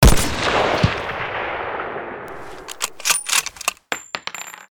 Battlefield: Bad Company 2 Sniper Rifle-sound-HIingtone